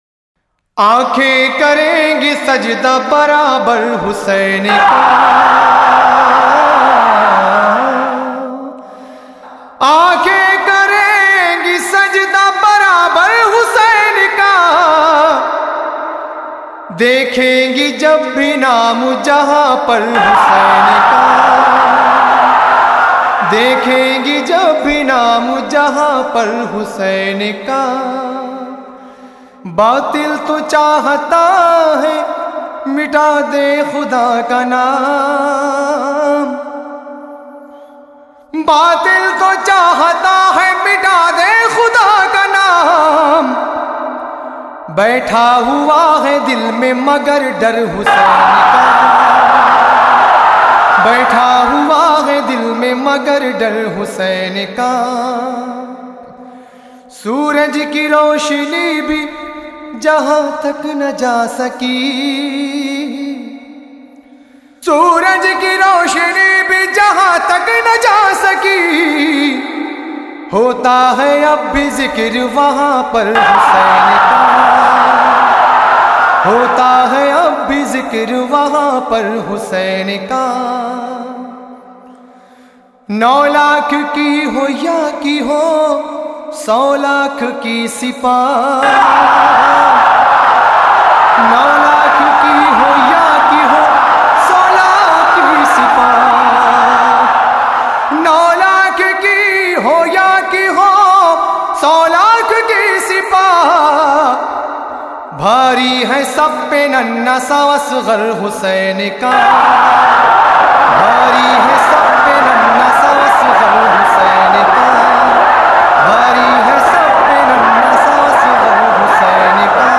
منقبتیں اور قصیدے